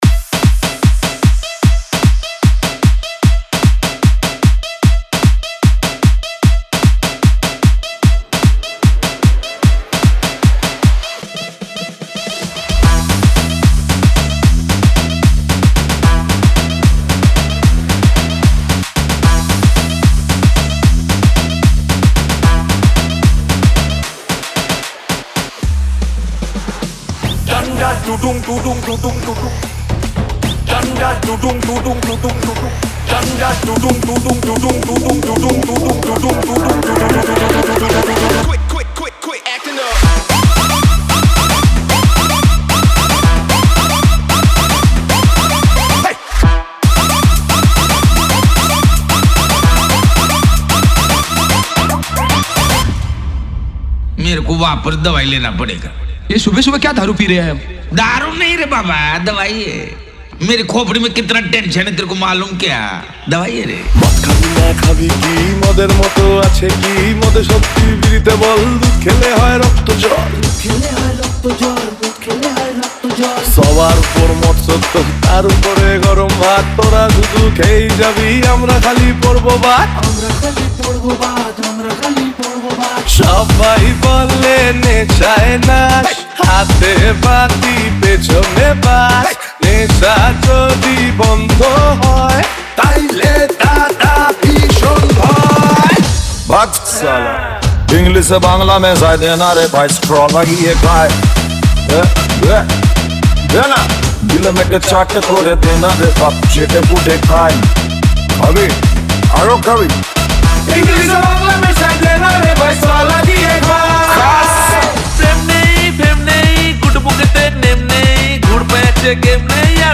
Tollywood Single Remixes